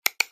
ClickOnOff.mp3